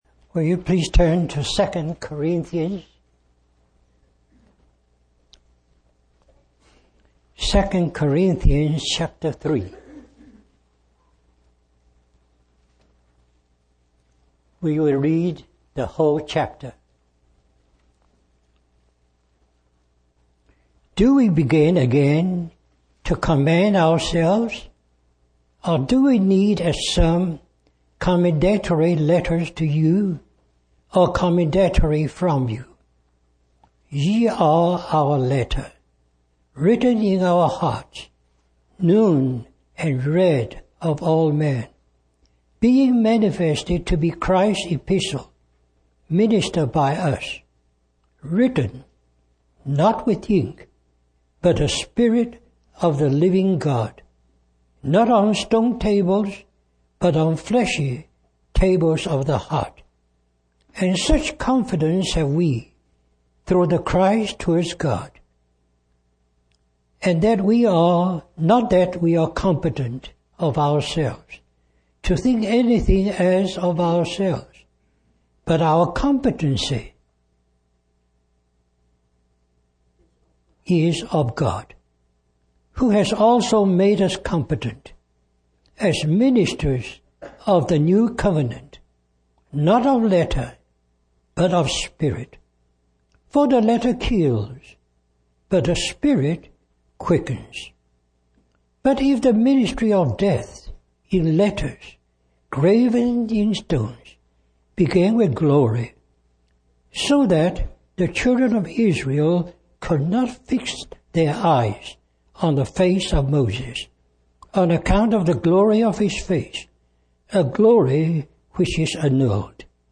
A collection of Christ focused messages published by the Christian Testimony Ministry in Richmond, VA.
Richmond, Virginia, US